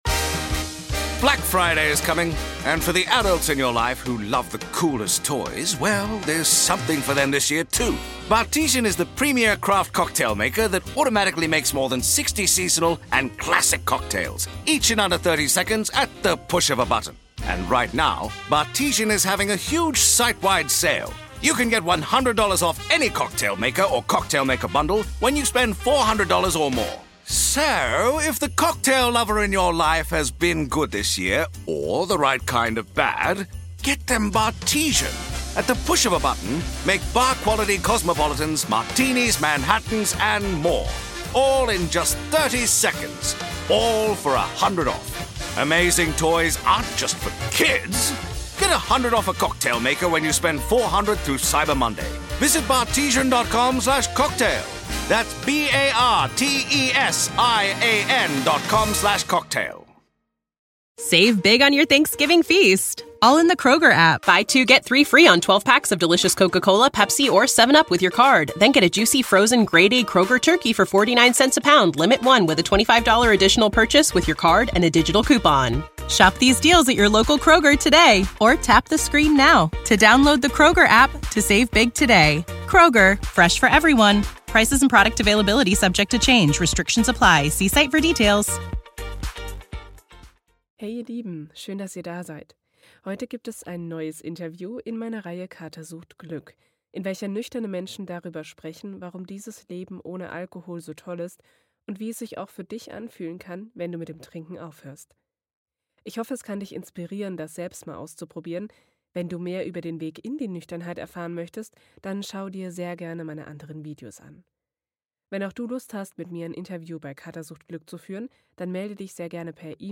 Endlich nicht mehr "cool" sein müssen⎜NiceDry-Event in Hamburg 2023 ⎜ Leben ohne Alkohol ~ Kater. Sucht. Freiheit. - Glücklich Nüchtern Podcast
Ich hoffe, das Interview gefällt euch - schreibt mir das gerne mal in die Kommentare!